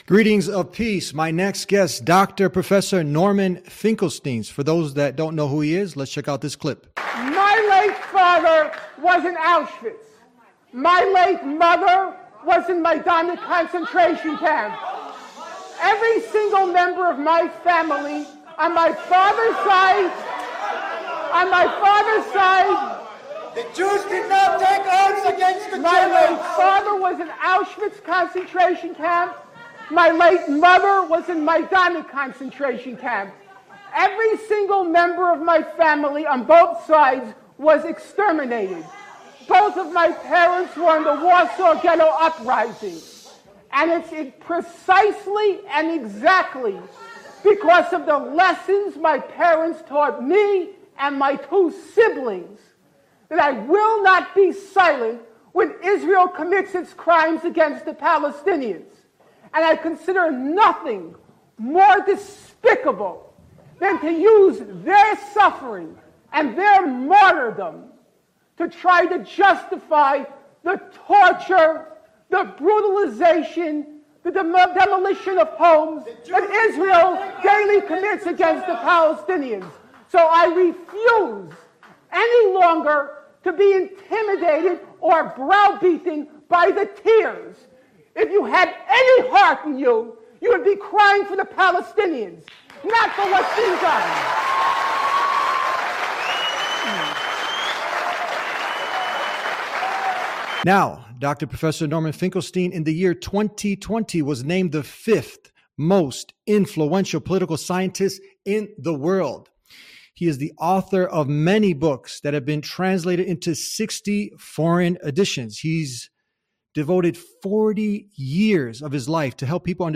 In this episode of The Deen Show, the guest, Professor Norman Finkelstein, sheds light on the misconceptions surrounding the Israeli-Palestinian conflict and...